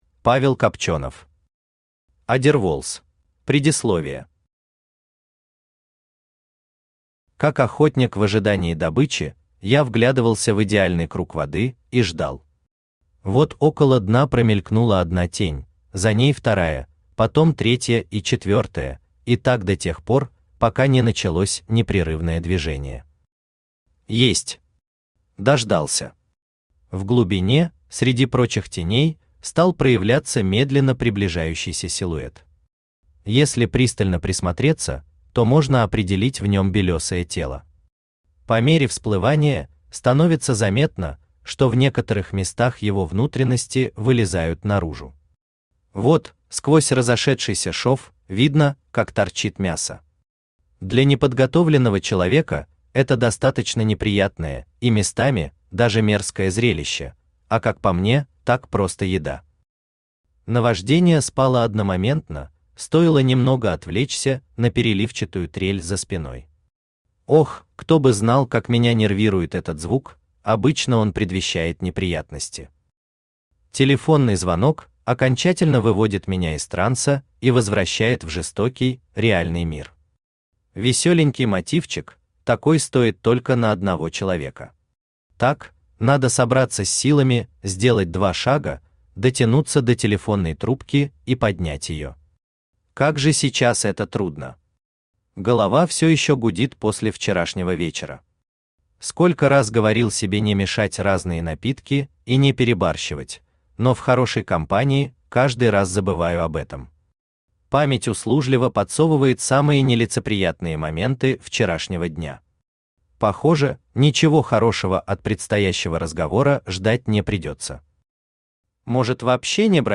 Aудиокнига Адерволз Автор Павел Николаевич Копченов Читает аудиокнигу Авточтец ЛитРес.